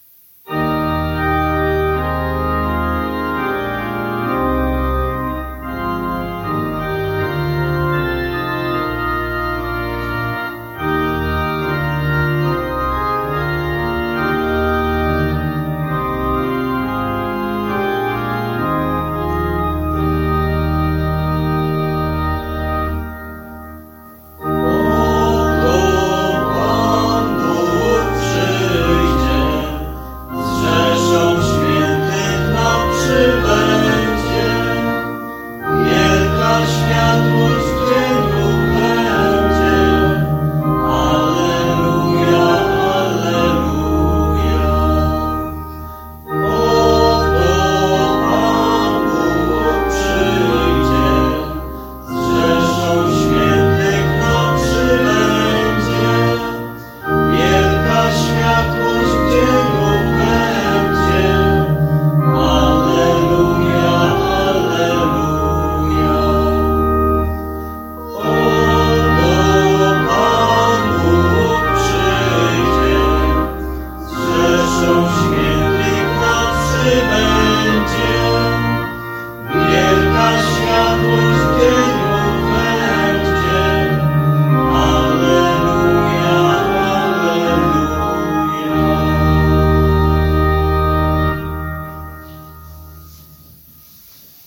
Adwentowe śpiewanie.
Dzisiaj śpiewaliśmy w oratorium.